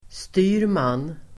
Ladda ner uttalet
Uttal: [st'y:rman]